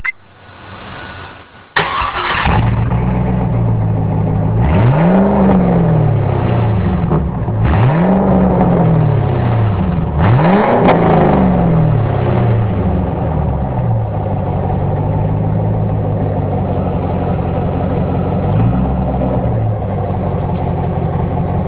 SLP Loudmouth cat-back exhaust: (Installed September 26th, 2002)
The new exhaust is LOUD. It brings to mind the sound of the late 60's to early 70's muscle cars. The sound at idle is much louder than stock and at wide open throttle it makes you think the world is coming to an end.
If you want to be heard before you are seen and like the sound of "almost" open pipes, the Loudmouth will not disappoint.
loudmouth.wav